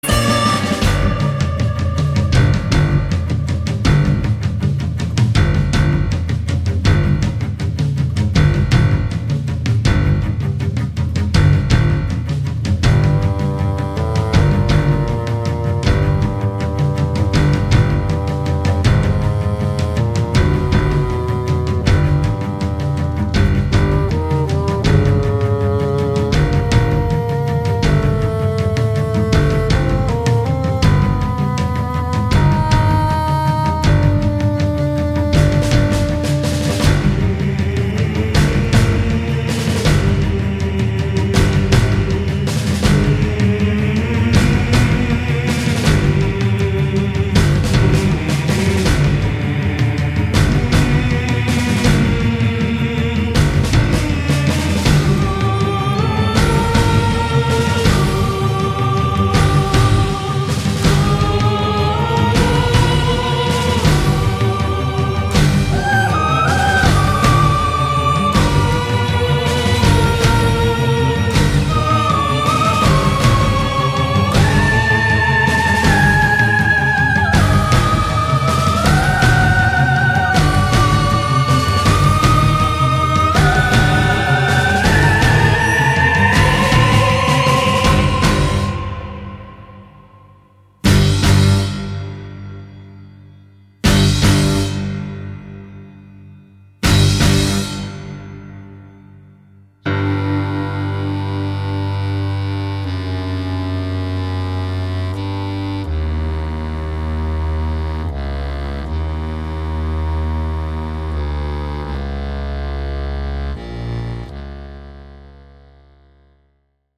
voice
violin
cello